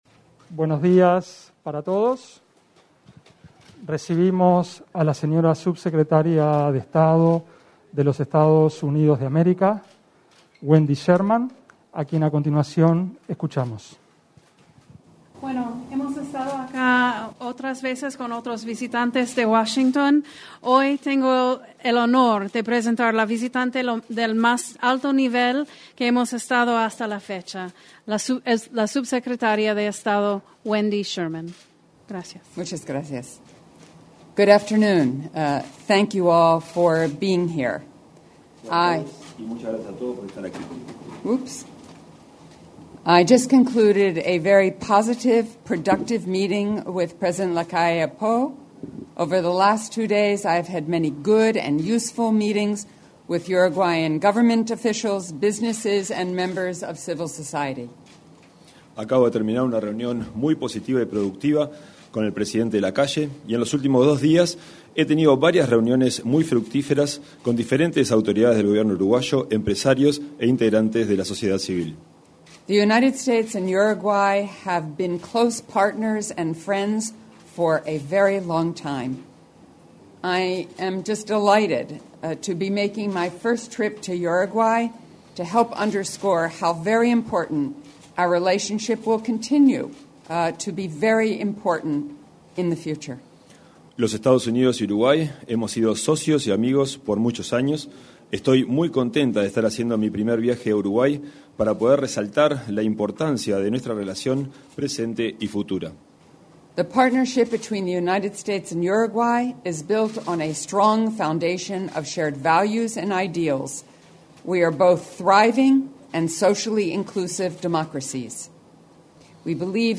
Conferencia de prensa de la subsecretaria de Estado de Estados Unidos de América
Conferencia de prensa brindada por la subsecretaria de Estado de Estados Unidos de América, Wendy Sherman, luego de su encuentro con el presidente de